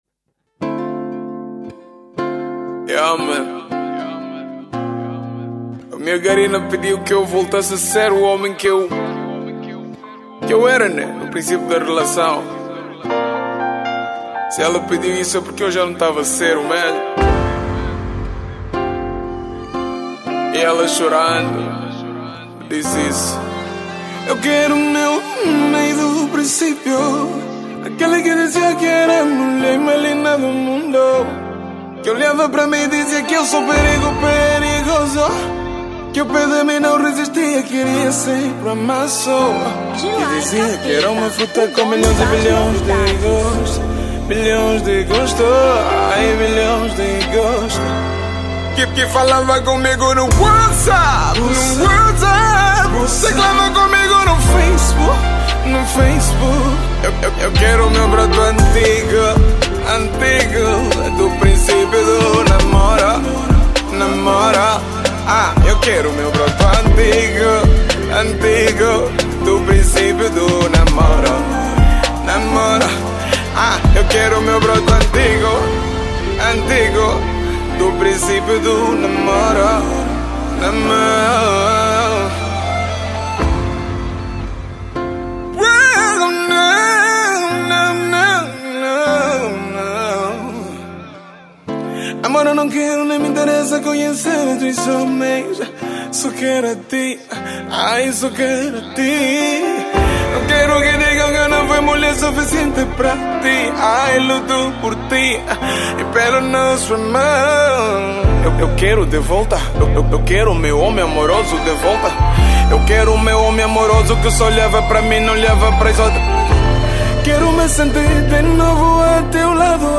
R&B 2015